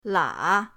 la3.mp3